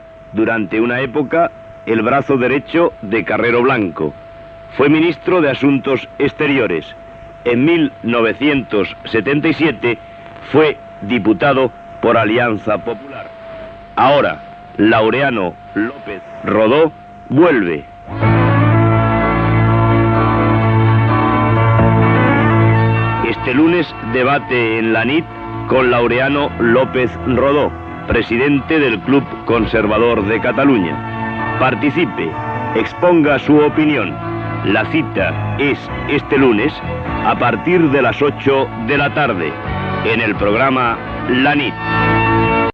Promoció del programa que es farà amb Laureano López Rodó.
Informatiu
Programa de debat i tertúlia política.